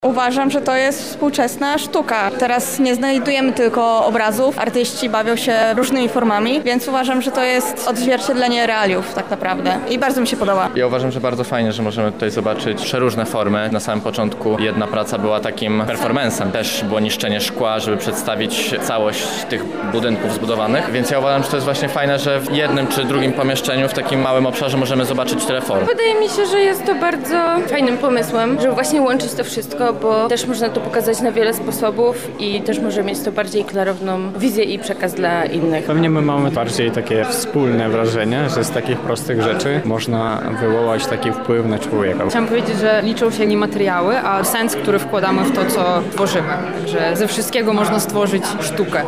Wystawa Inside Out, relacja
Opinie-widzow_01.mp3